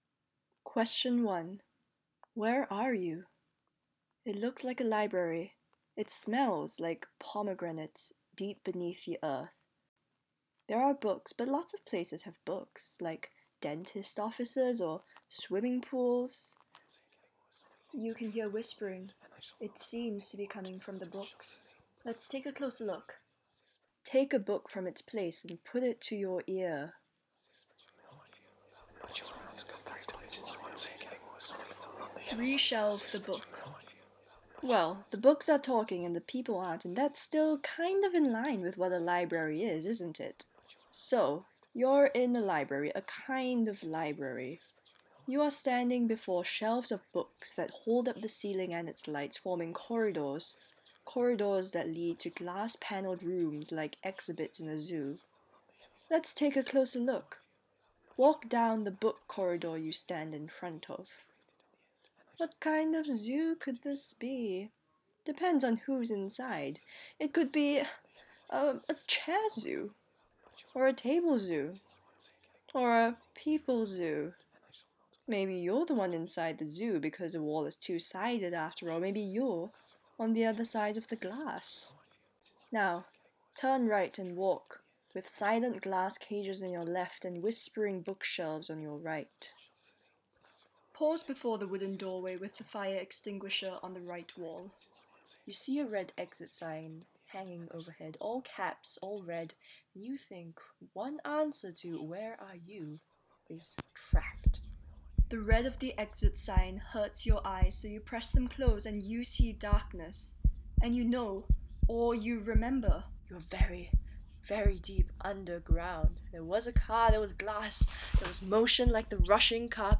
Audio Walk
I wanted to transform an ordinary space into something otherworldly, and to make the walk a journey rather than a narration. I thought that the classic journey out of the underworld was particularly suited to audio walk format and so adapted it.